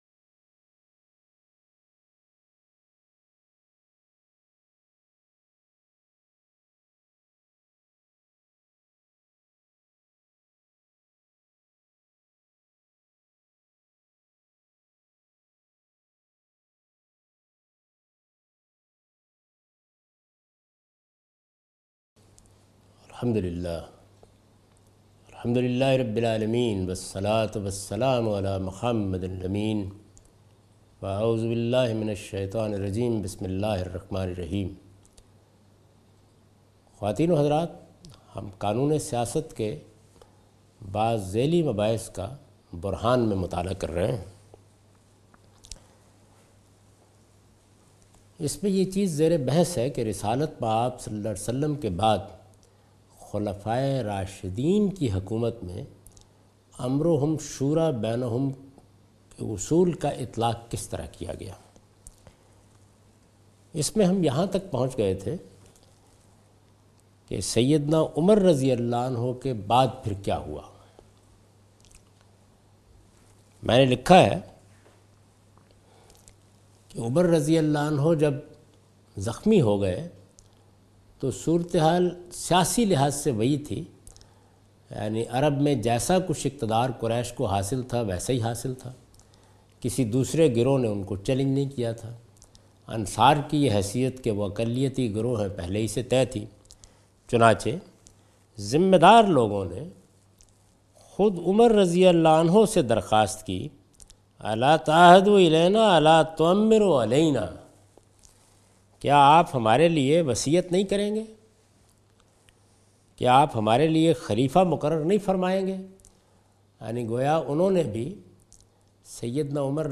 A comprehensive course on Islam, wherein Javed Ahmad Ghamidi teaches his book ‘Meezan’.
In this lecture he teaches the topic 'The Political Shari'ah' from 2nd part of his book. This sitting contains discussion on how government will be run in an Islamic socitey according to Quran and Sunnah.